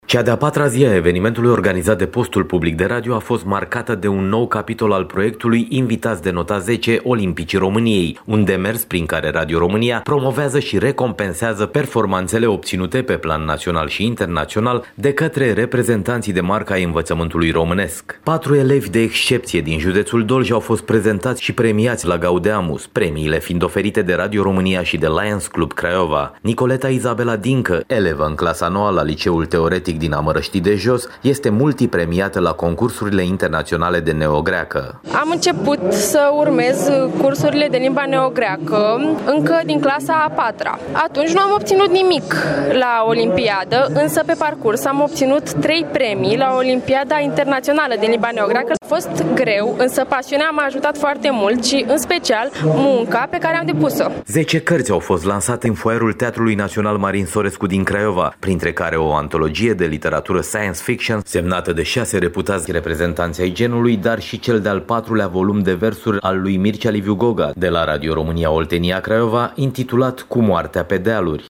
10 cărţi au fost lansate ieri în cadrul acestui târg, organizat de Radio România. Caravana Gaudeamus Radio România a premiat copiii cu rezultate deosebite din oraş. Relatează din Dolj